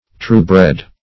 Search Result for " true-bred" : The Collaborative International Dictionary of English v.0.48: True-bred \True"-bred`\, a. 1.